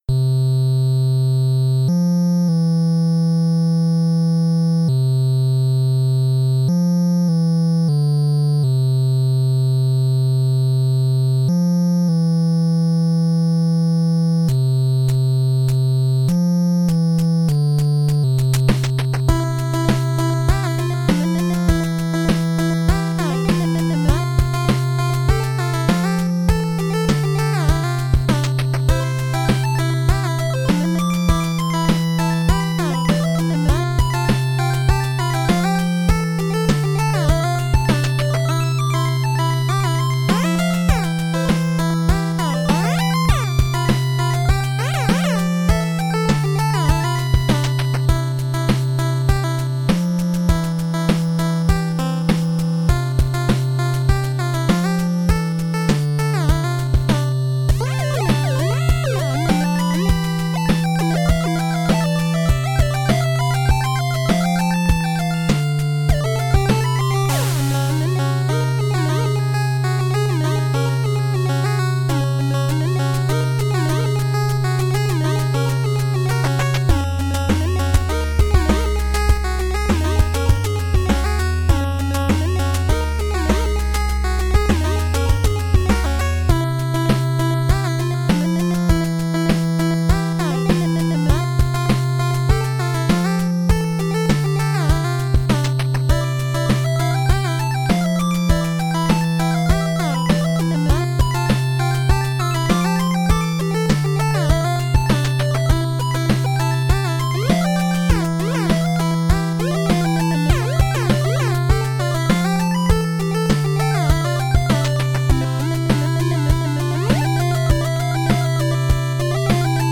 ditty i made me in the famitracker.. its pretty catchy lol